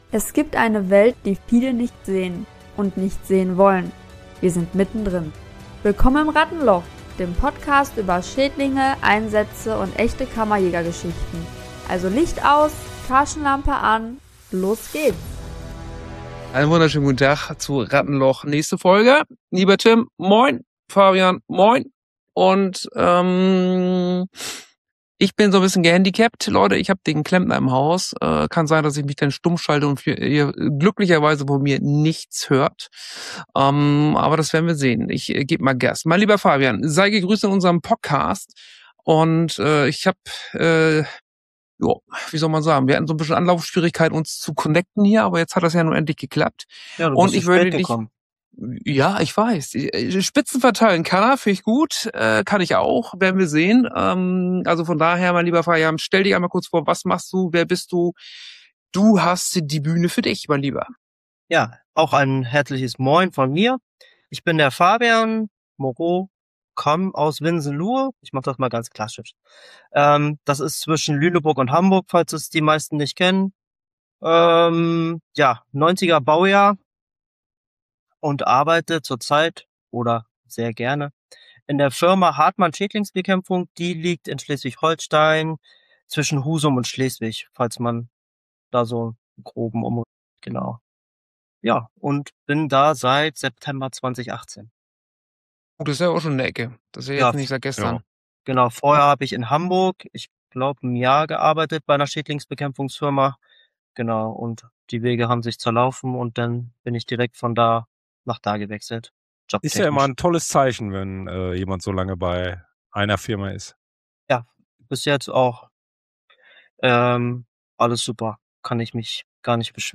Wie immer ungefiltert, direkt und ohne Blatt vor dem Mund.